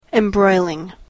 embroiling.mp3